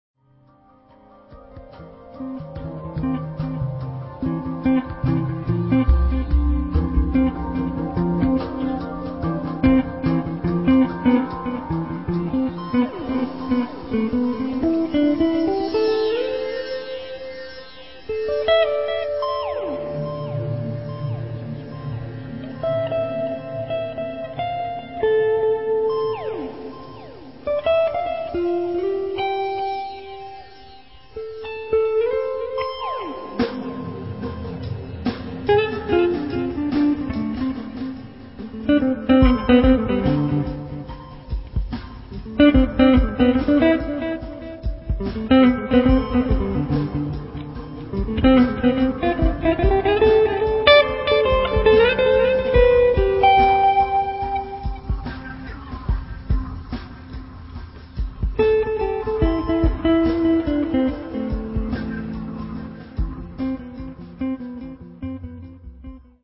They are all first takes.